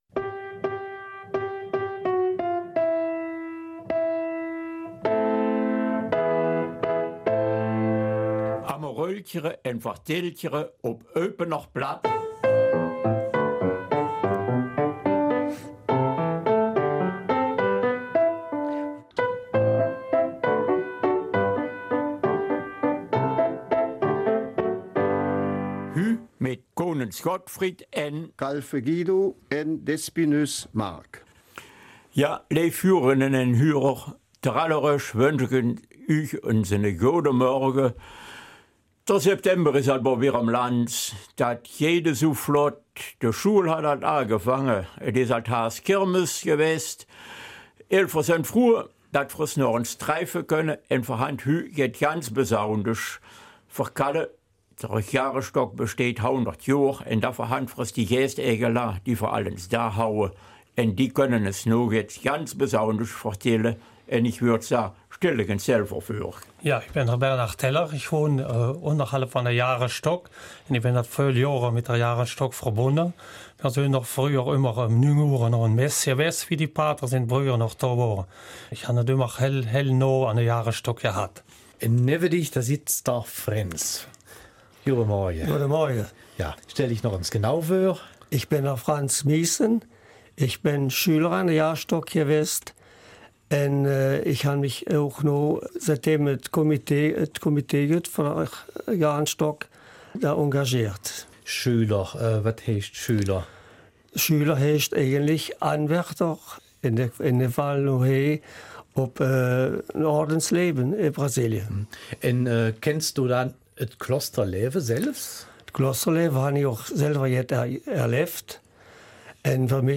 Am liebsten wäre es unseren beiden Studiogästen, dass wir sie gar nicht erwähnen, so sehr liegt ihnen ihr Lebensprojekt am Herzen.